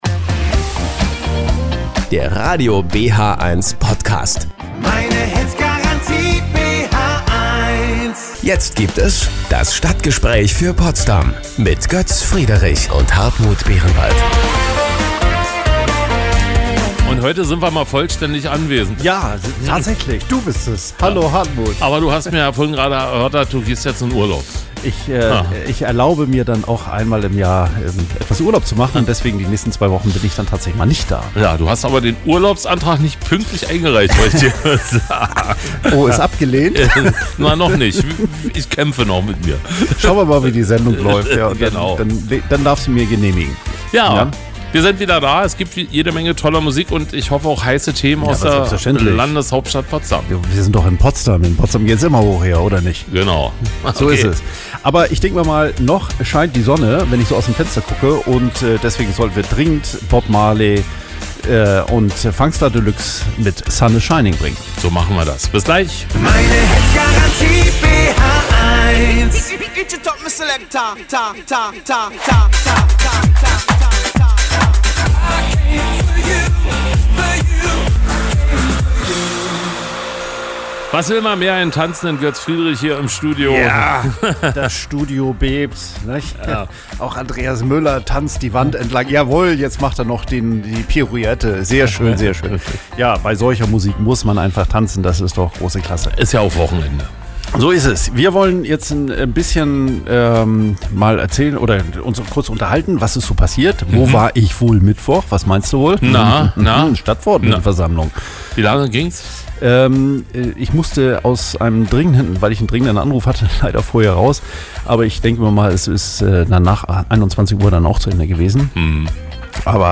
Ein Interview bei Radio BHeins: Das Stadtgespräch (vom 7.